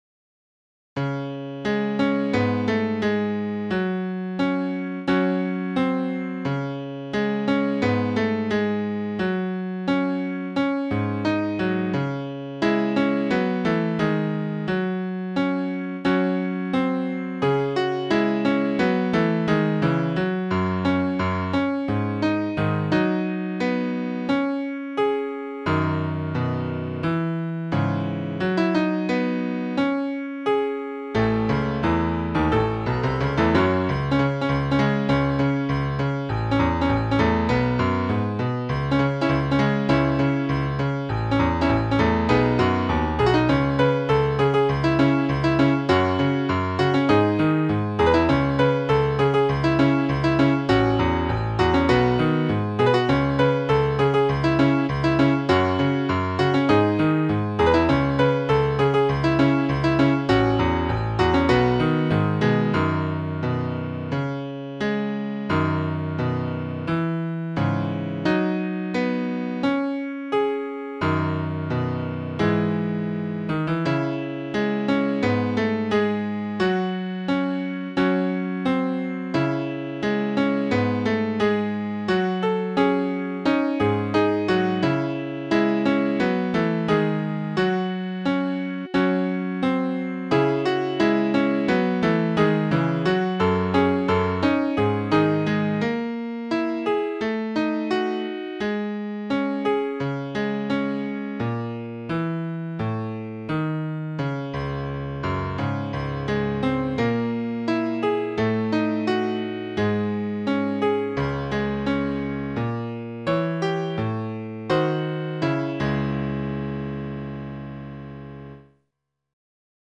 SAD MUSIC ; BALLADS